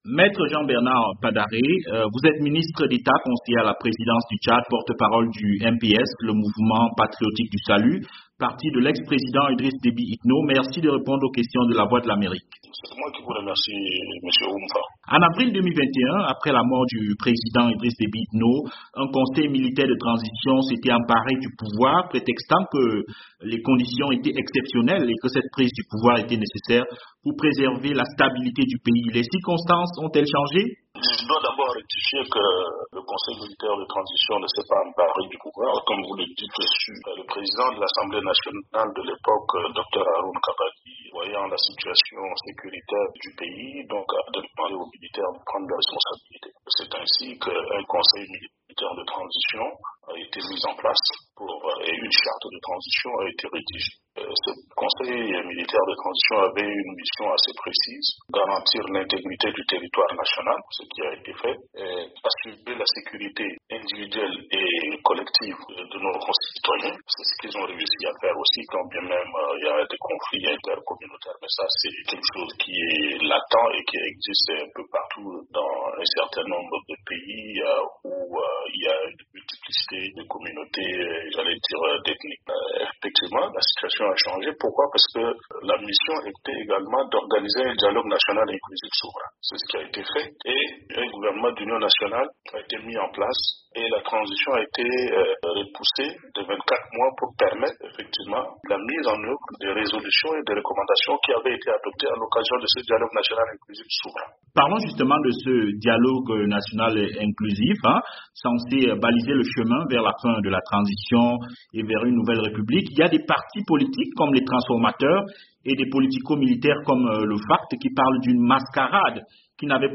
Au Tchad, la transition débutée au lendemain de la mort du président Idriss Deby Itno, se déroule normalement et le vote pour une nouvelle constitution aura bientôt lieu. C’est ce qu’a déclaré dans une interview exclusive à VOA, Me Jean Bernard Padaré, ministre d’Etat, conseiller à la présidence du Tchad et Porte-Parole du MPS (Mouvement patriotique du Salut), le parti du défunt Maréchal du Tchad.